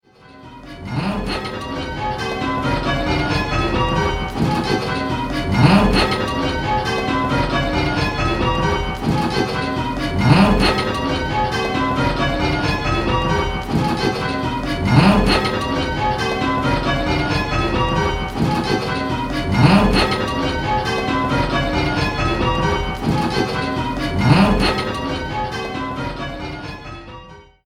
In this game you will be answering similar questions, but with 10 bells to choose from, the choices are much larger and there are more different notes to identify!